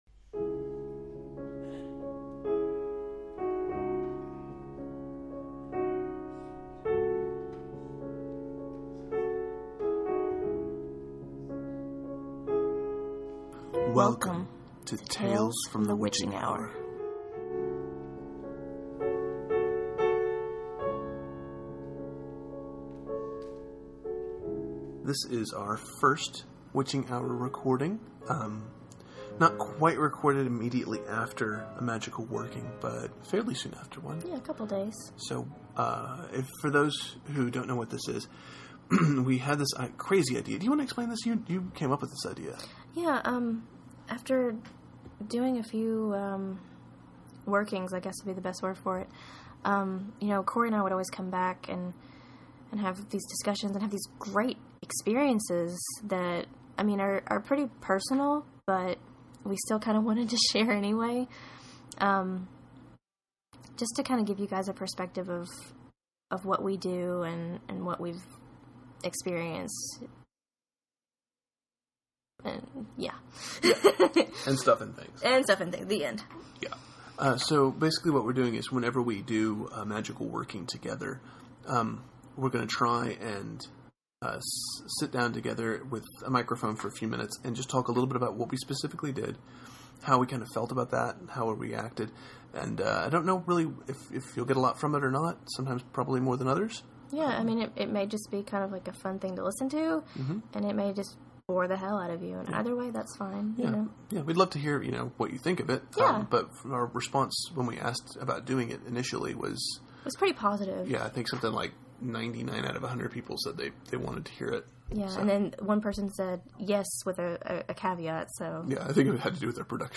These episodes will be chronicling our experiences with magical practices and rituals, and are more or less unedited and have very little production work done on them. Apologies for sound issues or other problems, but we hope you enjoy getting a little personal insight into what we do.